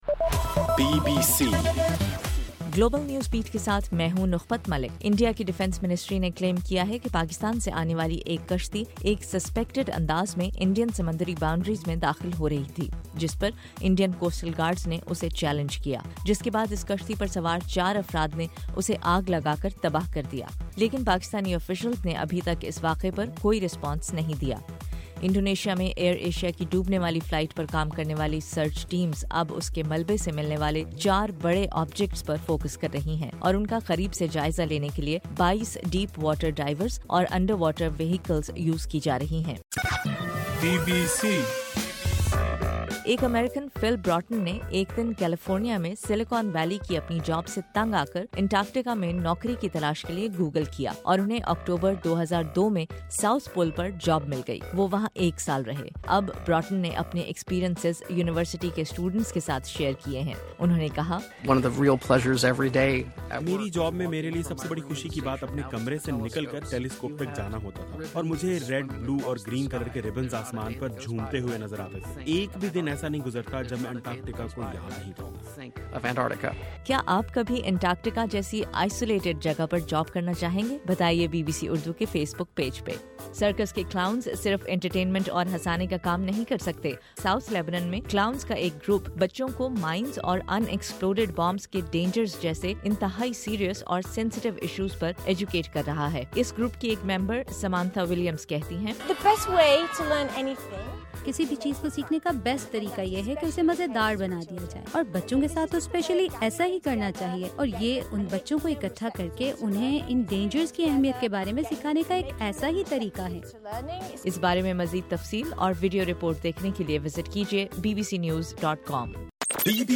جنوری 3: رات 9 بجے کا گلوبل نیوز بیٹ بُلیٹن